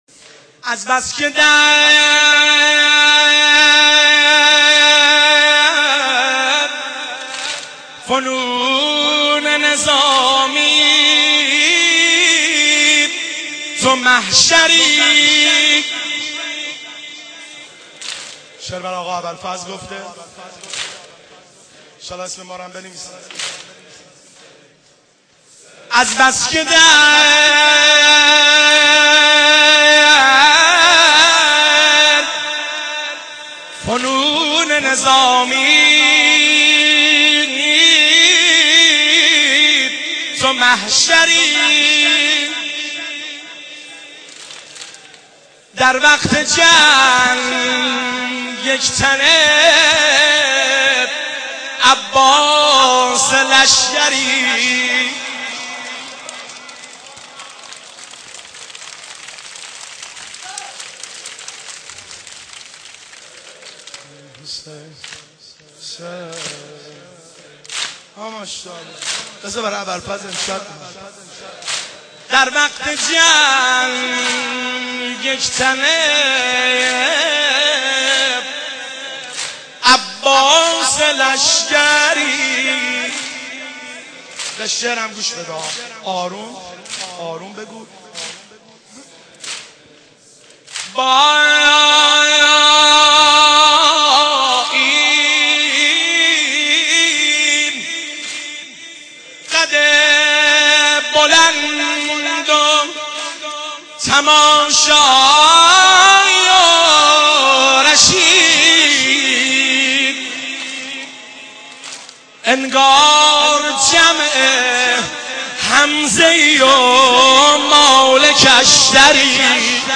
مراسم شب دهم محرم الحرام ۹۵ برگزار شد.
مراسم روضه خوانی و سینه زنی